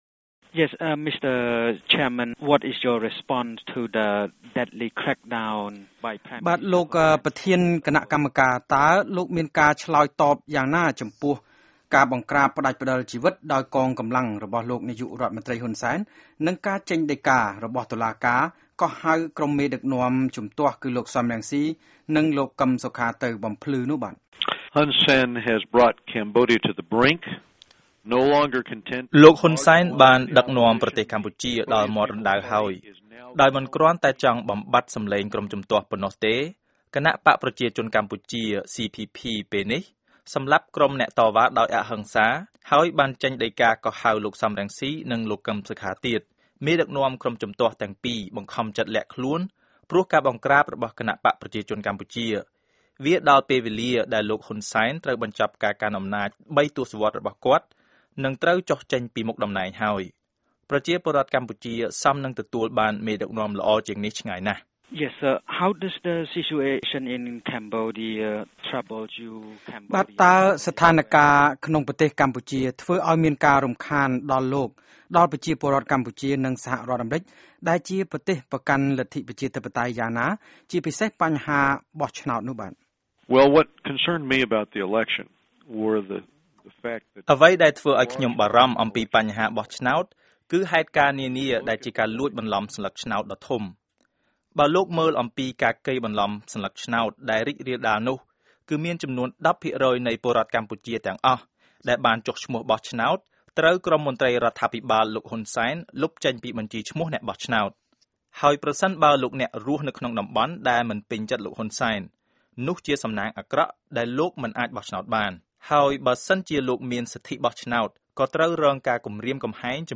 បទសម្ភាសន៍ VOA ជាមួយសមាជិកសភា Ed Royce